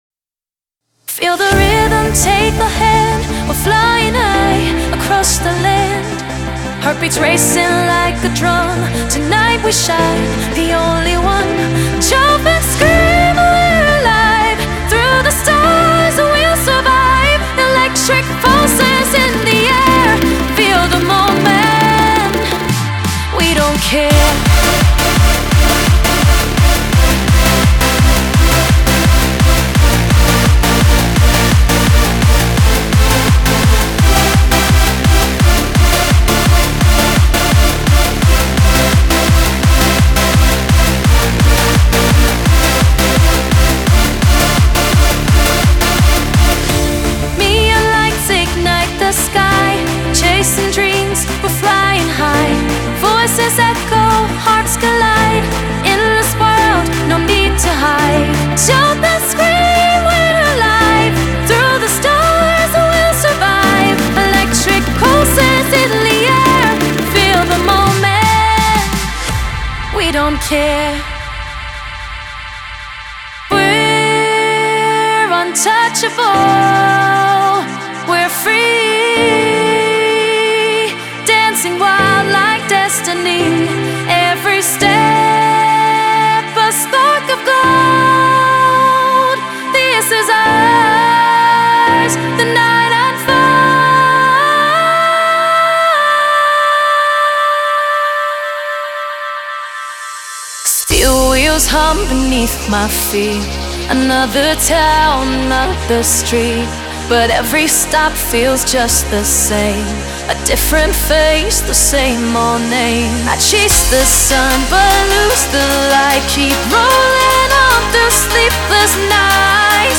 Hardcore / Hardstyle
5 Vocal Construction Kits
5 Acapella Vocals Dry-Wet